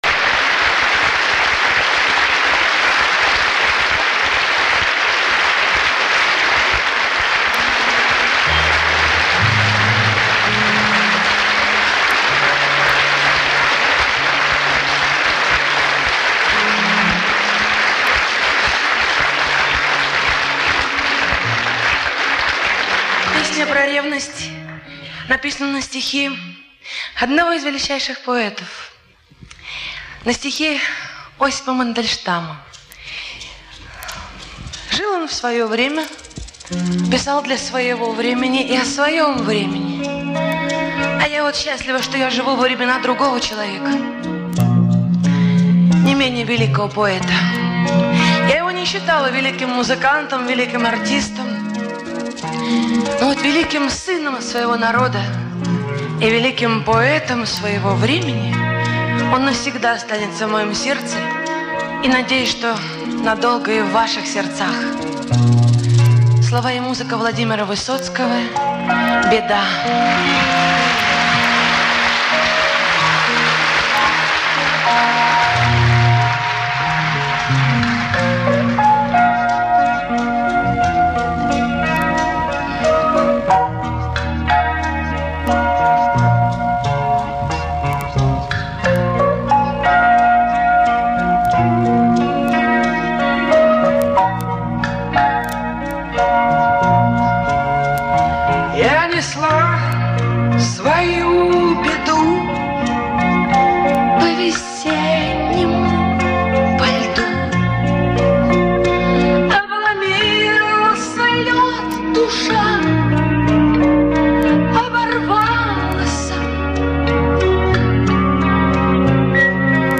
живое исполнение Юрмала 1981